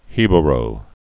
(hēbə-rō)